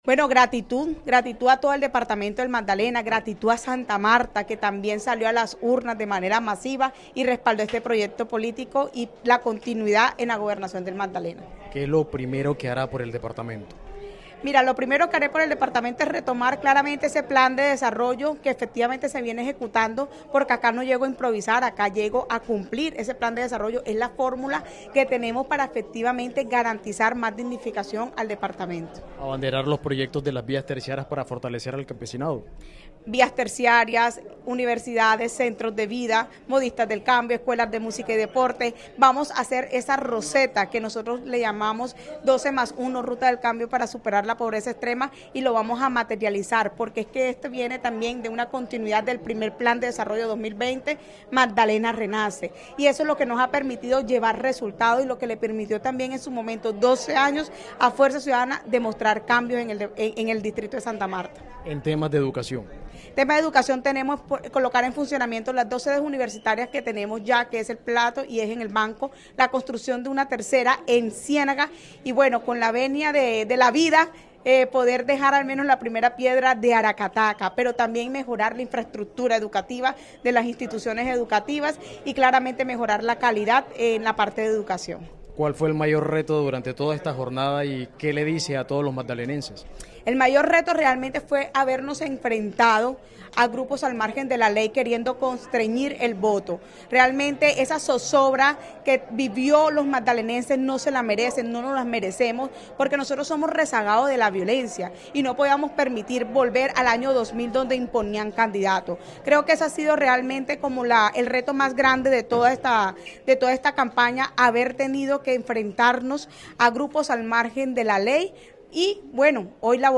La gobernadora electa del Magdalena, María Margarita Guerra, habló con Caracol Radio tras su victoria preliminar en las elecciones atípicas y aseguró que su principal compromiso será dar continuidad al proyecto político de Fuerza Ciudadana, movimiento que la impulsó como candidata y que vuelve a imponerse en el departamento.